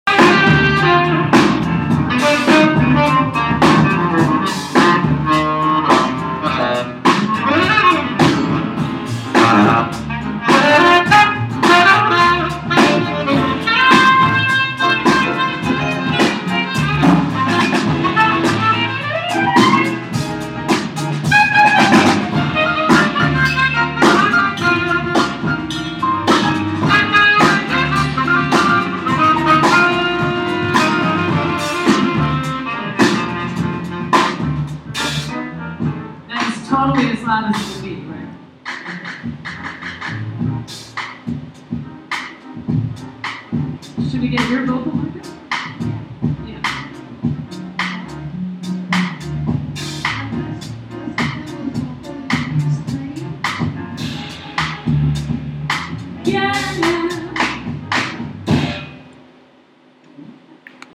Audio Recording 2-mp3 1) Monroe Theatre 2) This is a jazz band warming up with improv while doing a mic check before their concert.
2) This is a jazz band warming up with improv while doing a mic check before their concert. There is a bass, grand Piano, drum kit, sax and vocals.